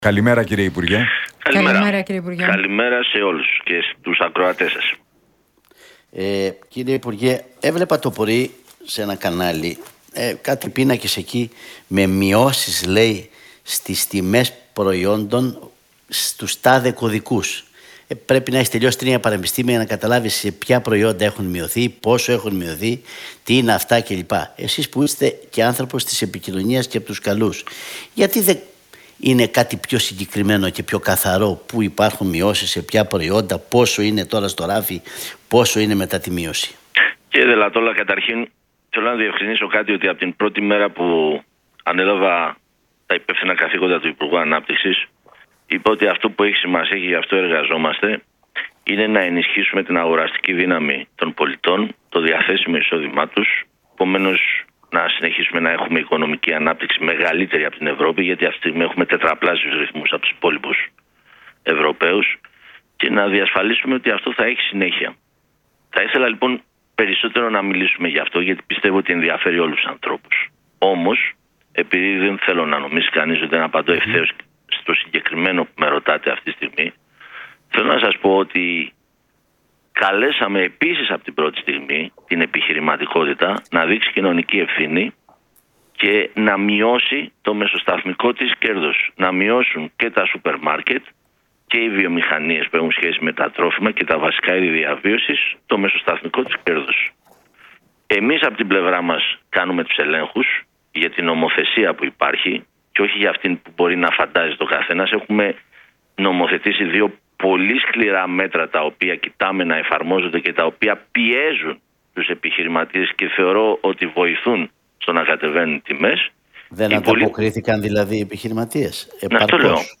Στο ζήτημα της ακρίβειας και στις μειώσεις των τιμών σε προϊόντα στα σούπερ μάρκετ, αναφέρθηκε ο Υπουργός Ανάπτυξης, Τάκης Θεοδωρικάκος, σε συνέντευξη που